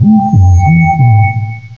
sovereignx/sound/direct_sound_samples/cries/musharna.aif at master